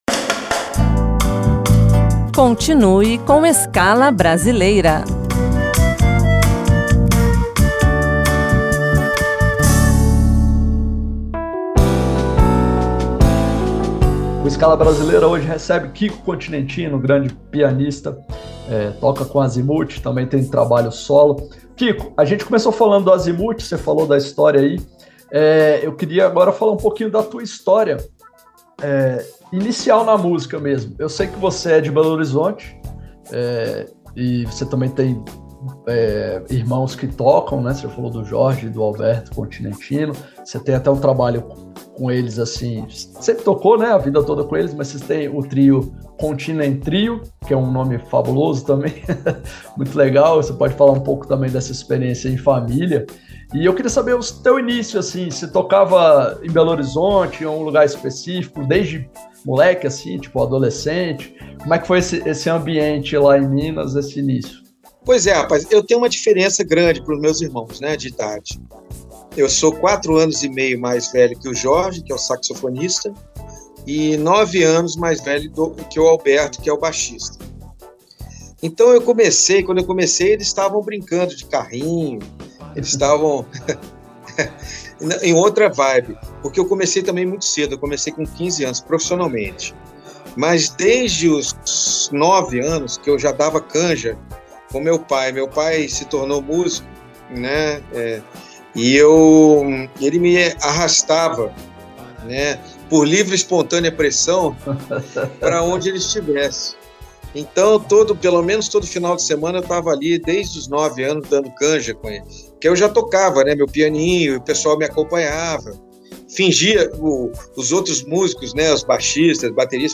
Nesta entrevista, além de falar sobre início da carreira aos 15 anos de idade, e da influência de sua família neste processo, o artista traz relatos da movimentada agenda do Azymuth, e como a banda tem agitado o underground pelos quatro cantos do planeta. Ouça a primeira parte da entrevista.